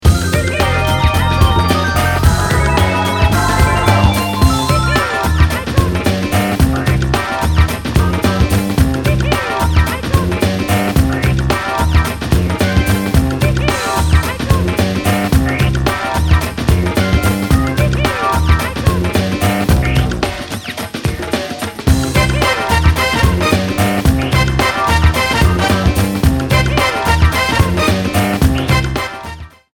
Intro and ending trimmed, added fadeout
Fair use music sample